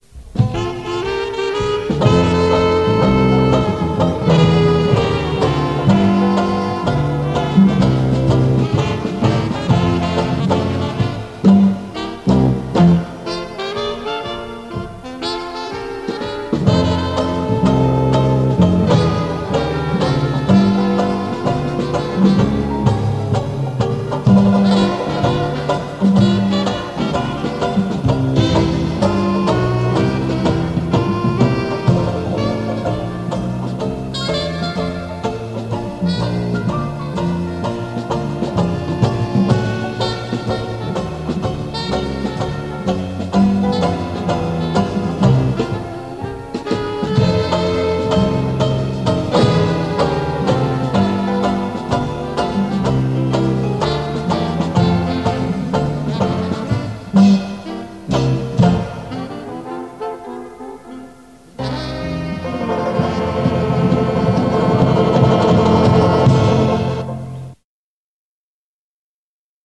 The Paradise Jazzband Music music / jazz
Their music style initially was New Orleans and old style Dixieland jazz, and later more modern Dixieland and Swing.
Luister naar de Intro Tune (“I Ain't Got Nobody”) die de Paradise Jazzband vele jaren aan het begin van hun optredens heeft gespeeld (hier bij het optreden tijdens hun 20-jarige jubileum op 2 november 1991 in de Oosterhoutse Bussel):